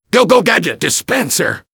engineer_autobuildingdispenser02.mp3